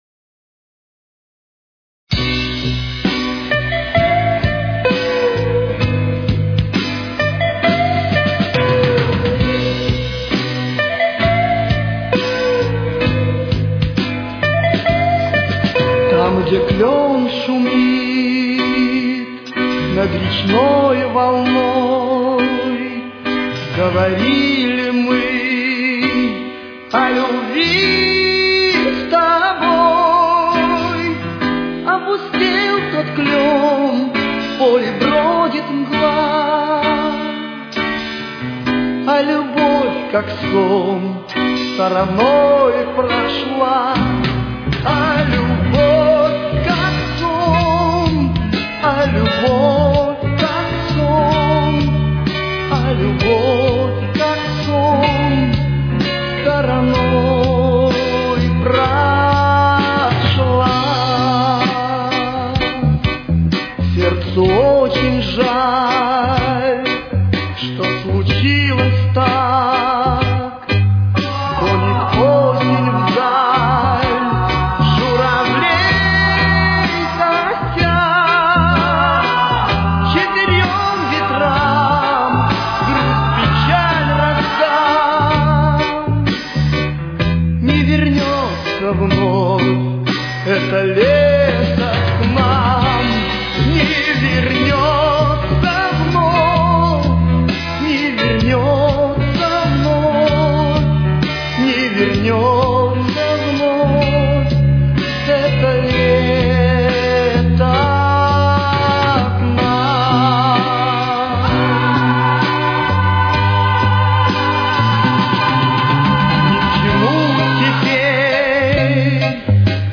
с очень низким качеством (16 – 32 кБит/с)
Тональность: Си минор. Темп: 67.